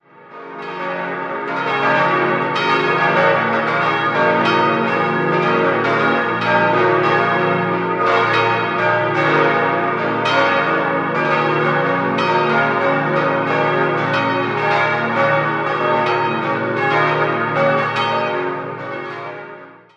Prien am Chiemsee, Pfarrkirche Mariä Himmelfahrt Die Marktgemeinde Prien liegt am Westufer des Chiemsees. In den Jahren 1734 bis 1738 wurde die Pfarrkirche im spätbarocken Stil neu errichtet, Stuck und Deckengemälde stammen von Johann Baptist Zimmermann. 5-stimmiges Geläut: h°-c'-e'-gis'-h' Die Glocken 6, 4, 2 wurden 1952, die große 1959 von Karl Czudnochowsky in Erding gegossen.